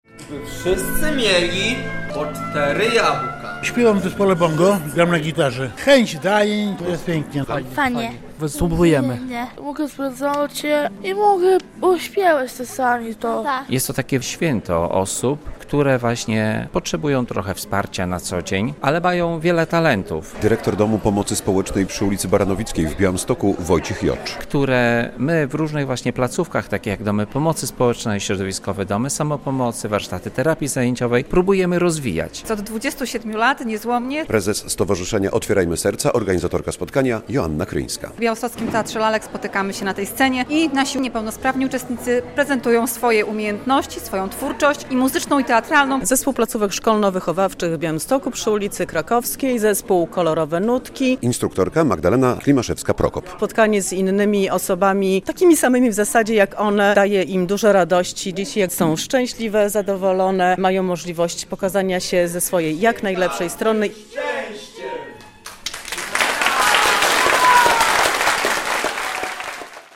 Około 200 niezwykłych artystów prezentuje swoje wokalne, taneczne i aktorskie talenty w Białostockim Teatrze Lalek. Trwa tam XXVII Przegląd Twórczości Artystycznej Osób Niepełnosprawnych.